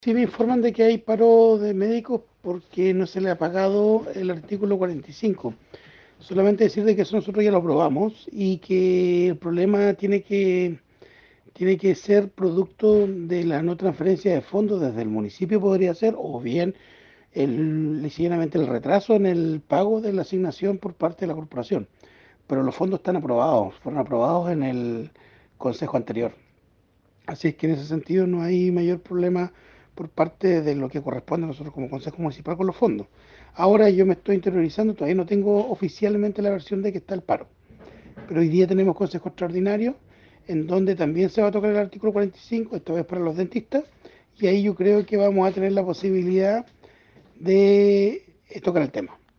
A esta situación el concejal Andrés Ibáñez señaló que los recursos para los profesionales médicos ya fueron aprobados por el concejo municipal y que podría haber un retraso en el pago del Bono por parte del municipio y la corporación.